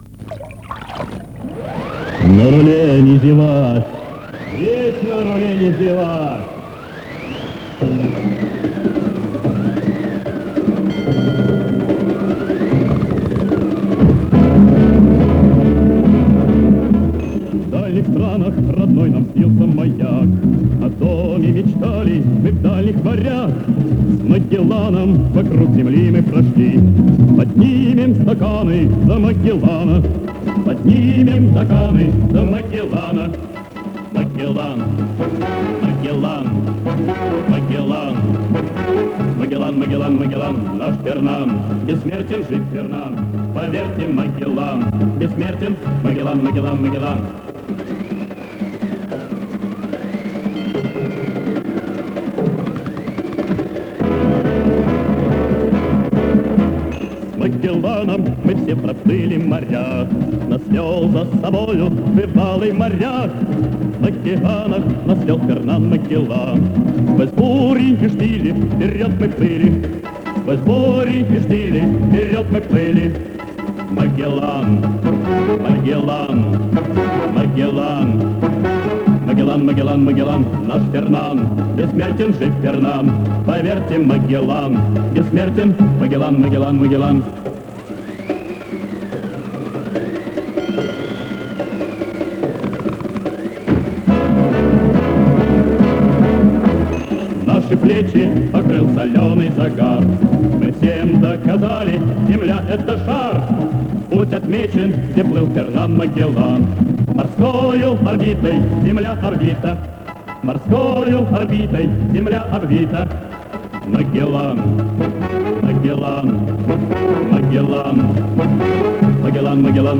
Мужской голос.
Но  на кассете.